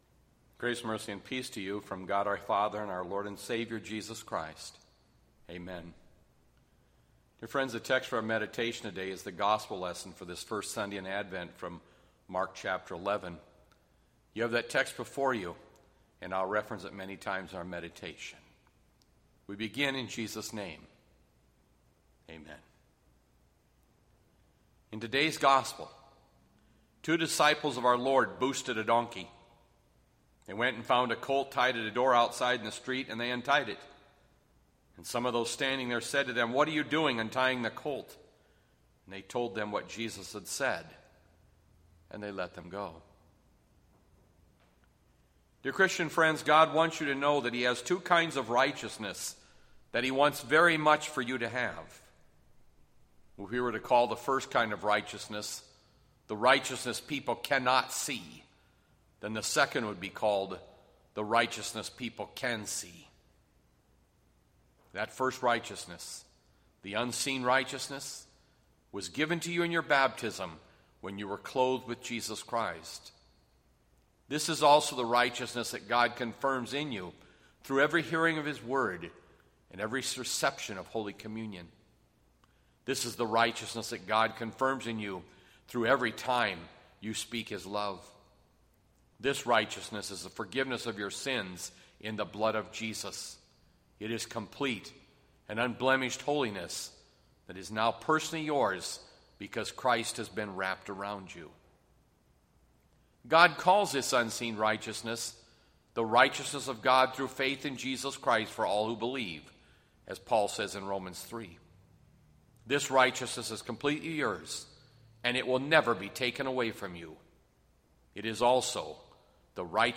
Nov 29, 2020  SERMON ARCHIVE